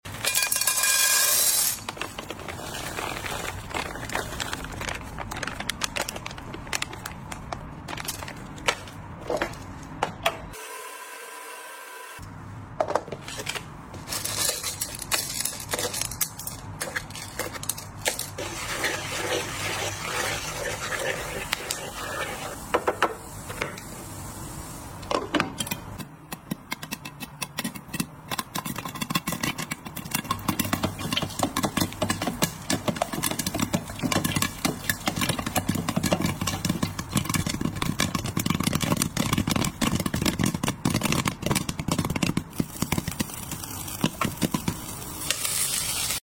Hear every kernel pop into sound effects free download
Hear every kernel pop into a crispy, satisfying symphony… popcorn has never been this captivating!